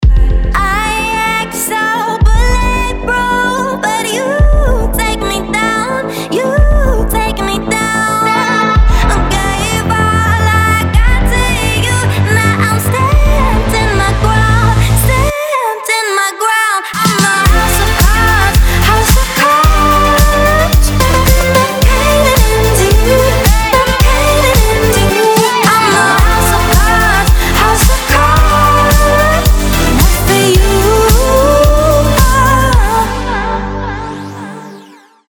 • Качество: 320, Stereo
женский вокал
dance
Electronic
Midtempo
красивый женский голос